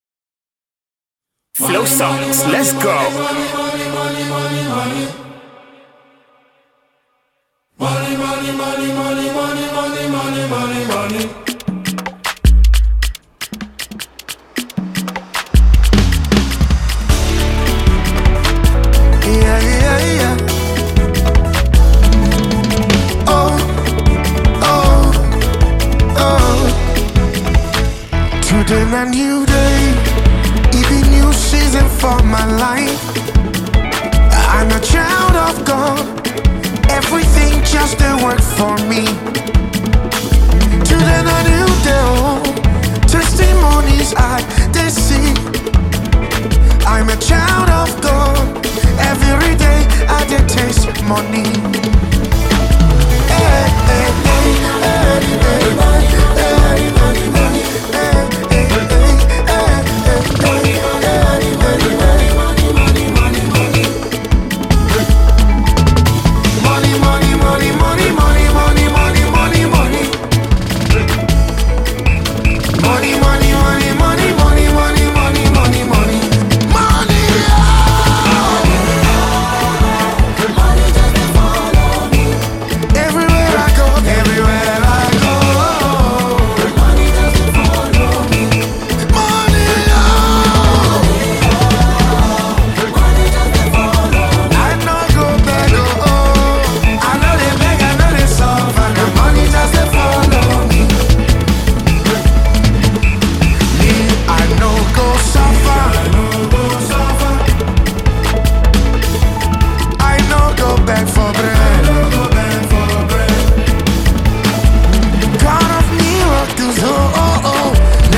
GOSPEL
It is a catchy and lively melody.
Highlife and Afrobeat